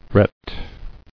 [ret]